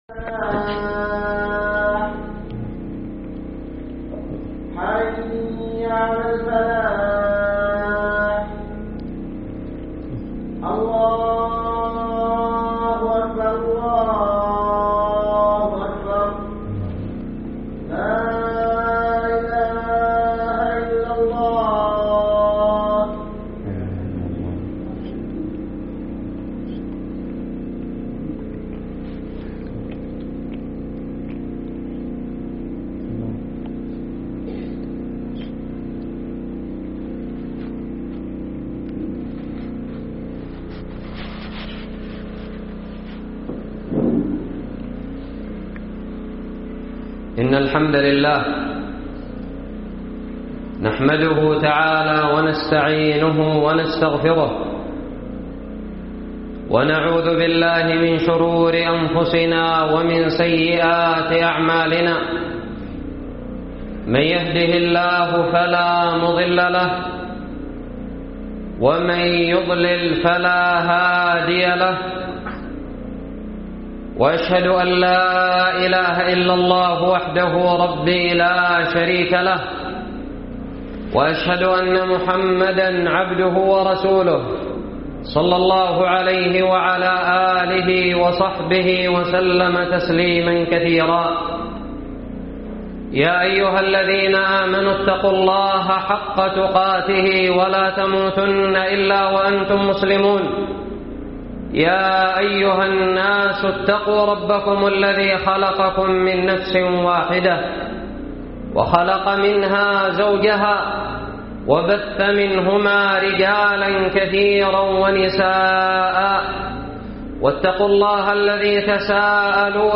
خطب الجمعة
ألقيت بدار الحديث السلفية للعلوم الشرعية بالضالع في 3 ربيع ثاني 1436هــ